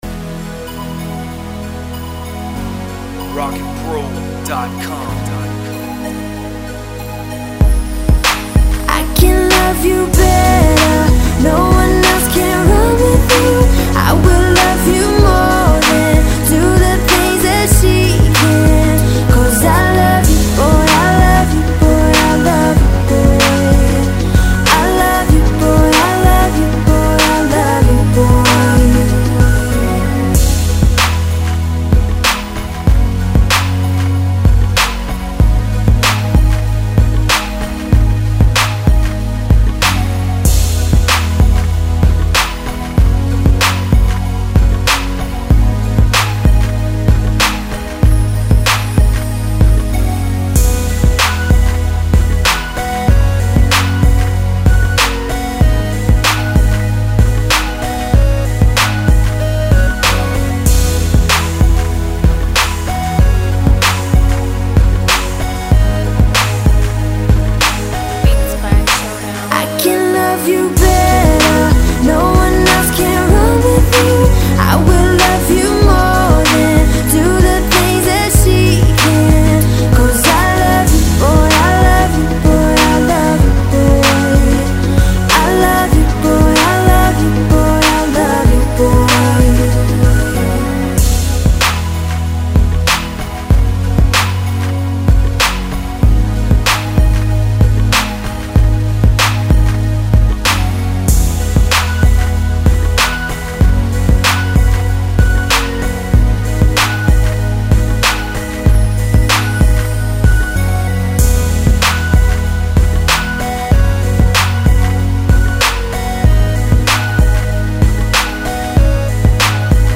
95 BPM.
beat with a catchy hook
Beats With Hooks
Club
Pop